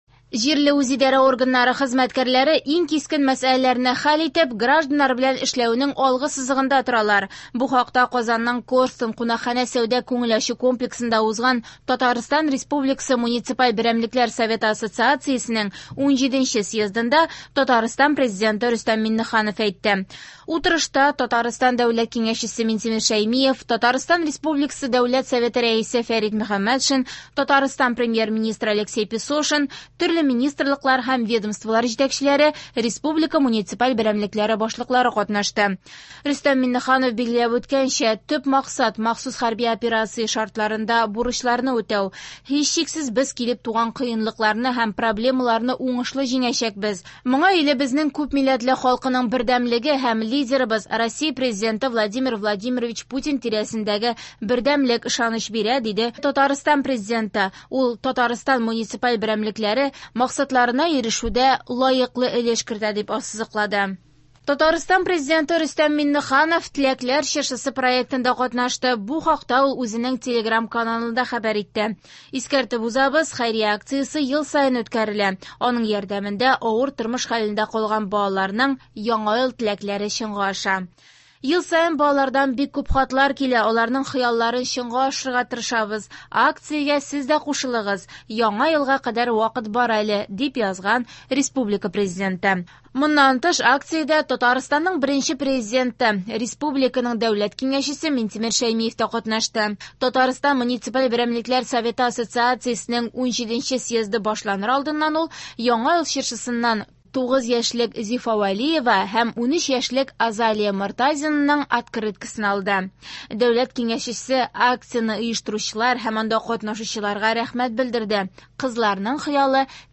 Яңалыклар (21.12.22)